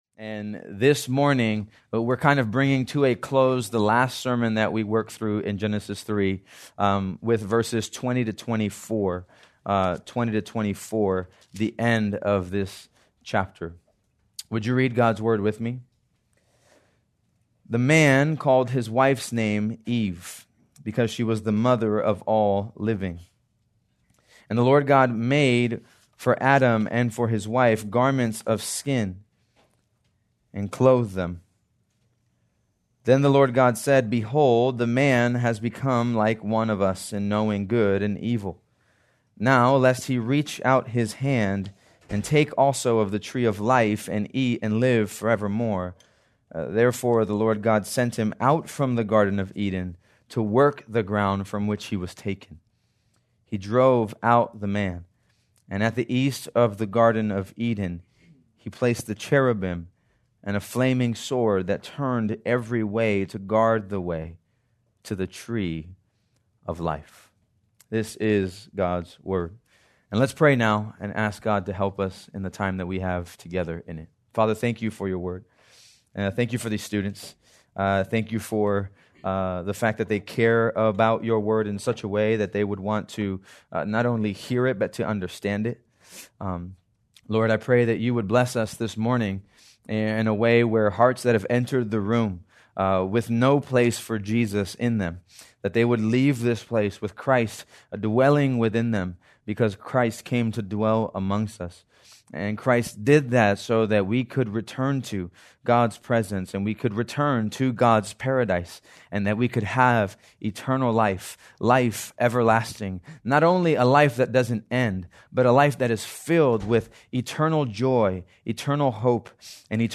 March 29, 2026 - Sermon | 180 Ministry | Grace Community Church